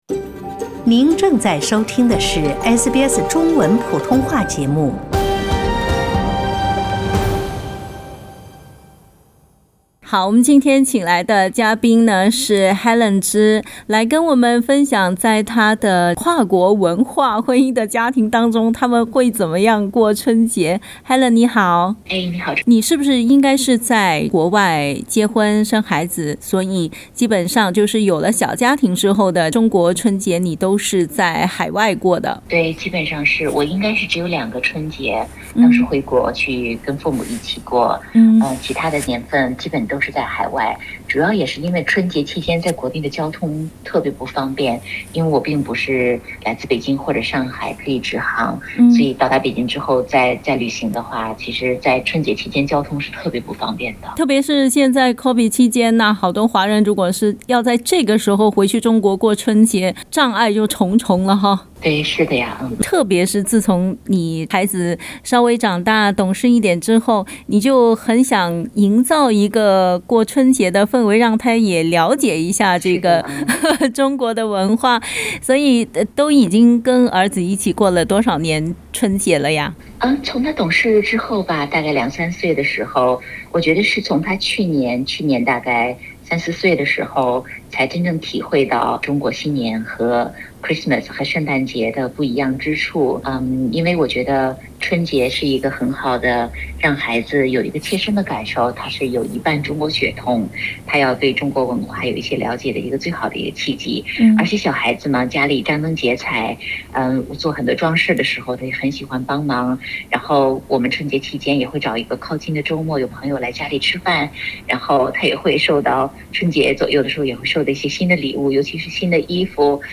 在跨国婚姻家庭里，怎样才能让家里的小朋友了解中国人过春节的习俗呢？(点击图片收听采访）